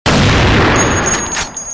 sound_gun2.ogg